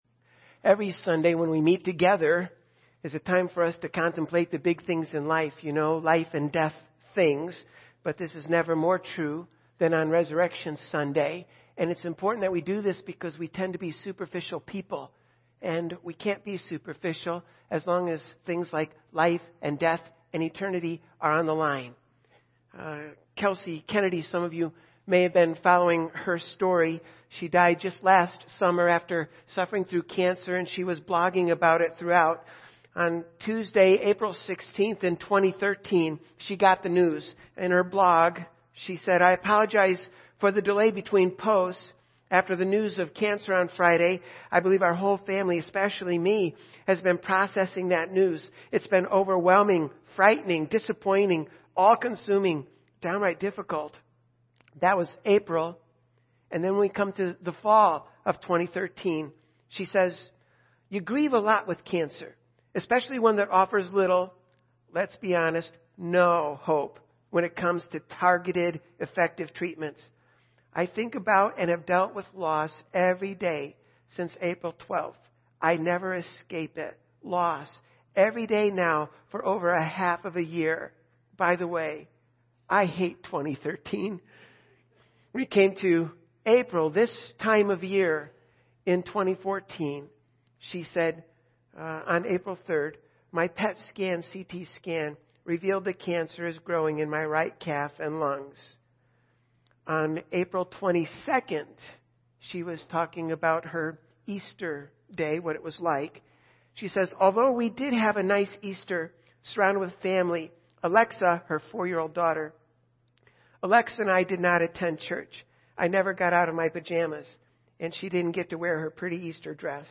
Easter Service